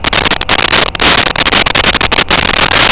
Sfx Amb Platformhover Loop
sfx_amb_platformhover_loop.wav